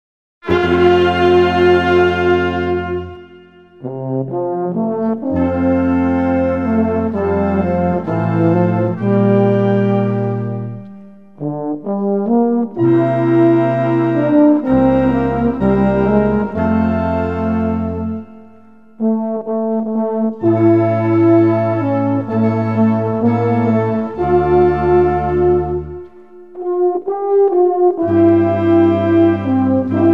Gattung: Choral (B-Dur)
Besetzung: Blasorchester